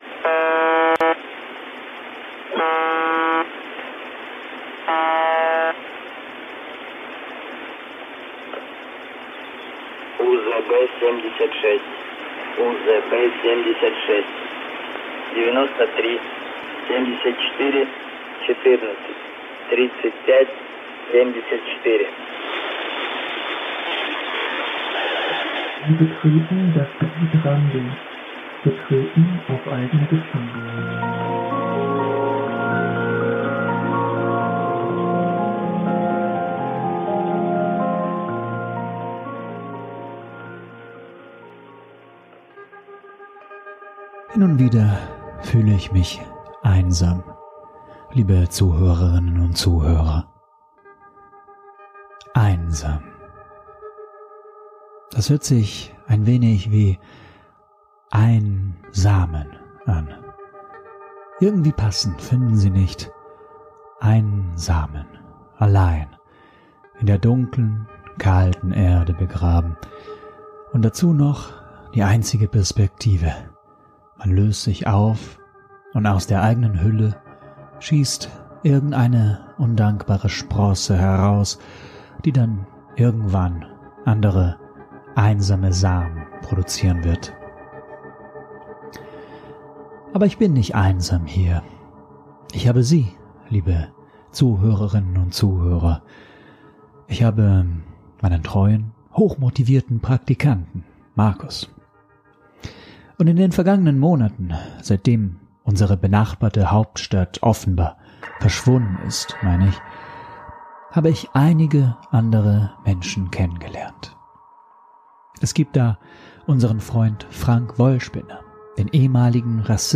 SFX: UVB-76